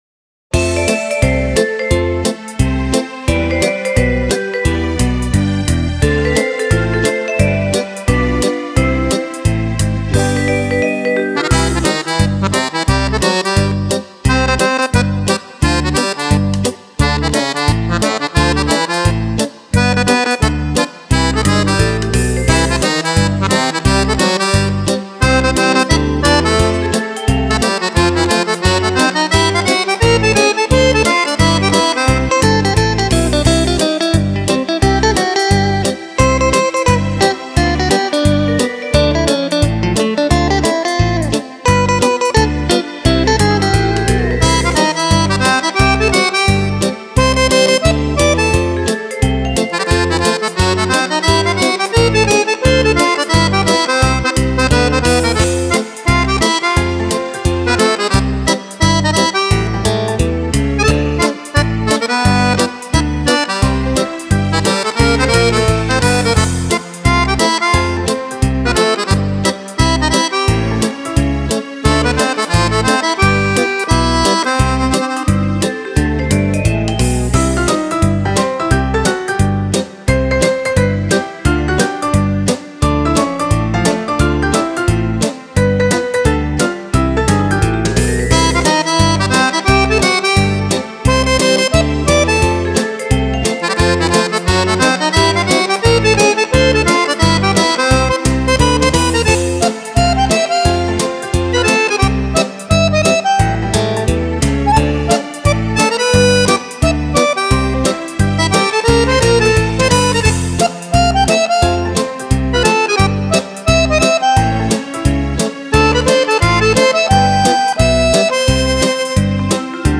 10 ballabili per Fisarmonica
Fox-trot